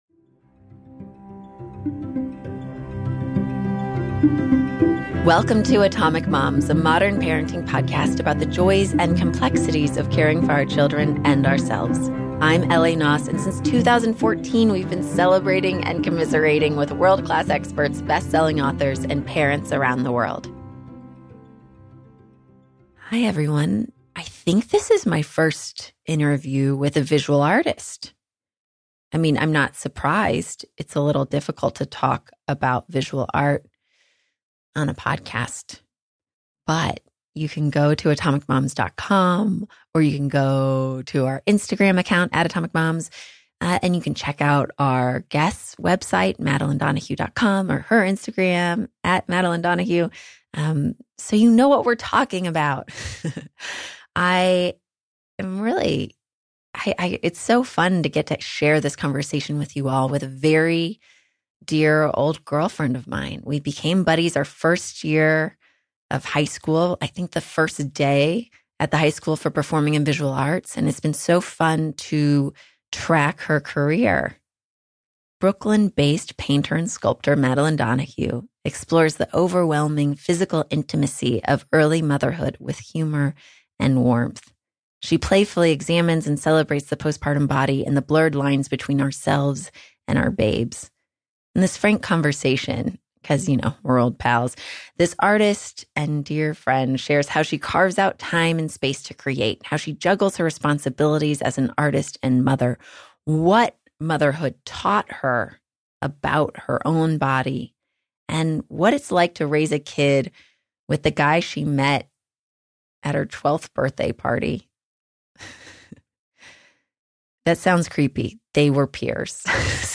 In our conversation